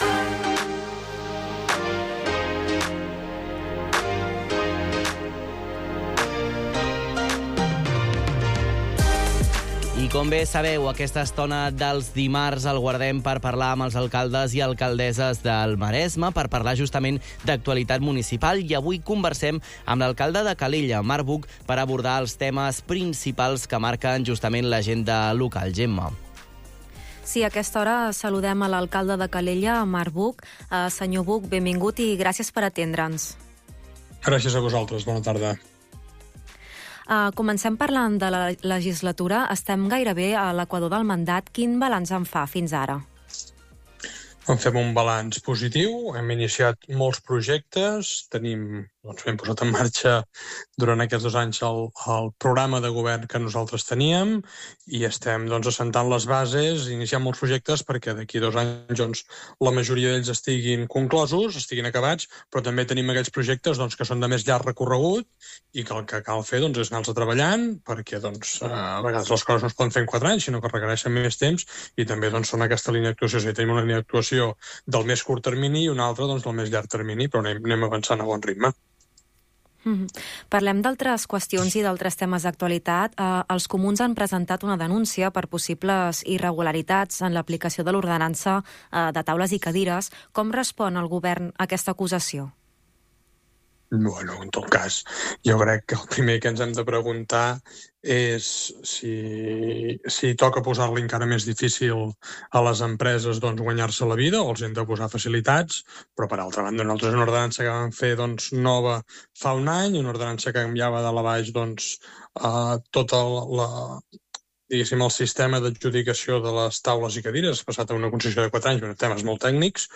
Ona Maresme: Entrevista amb l’alcalde, Marc Buch
Avui és el torn de l’alcalde de Calella, Marc Buch. Conversarem amb ell sobre els principals temes que afecten el municipi, els projectes en marxa i els reptes de futur, coincidint amb l’equador del mandat municipal.